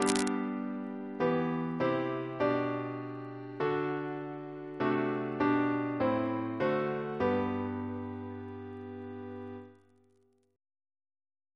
CCP: Chant sampler
Single chant in G Composer: Edwin George Monk (1819-1900), Organist of York Minster Reference psalters: ACB: 378